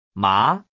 The 2nd tone “ˊ” like in má Rising (